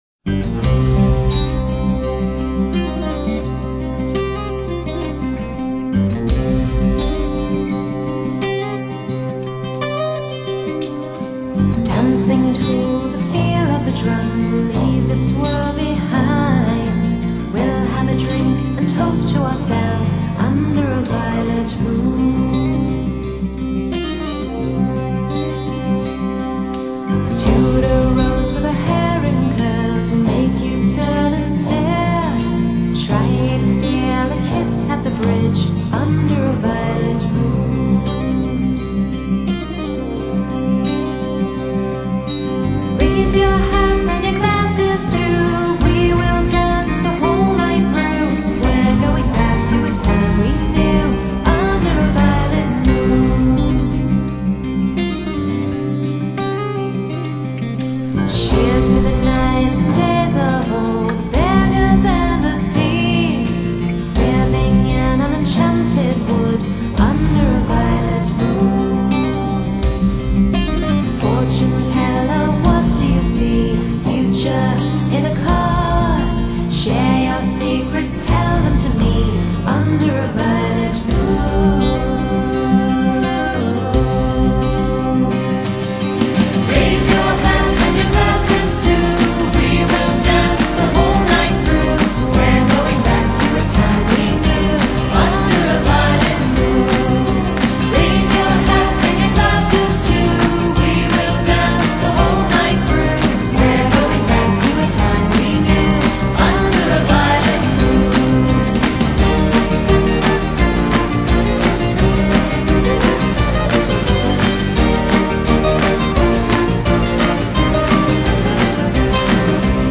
Folk rock, neo-medieval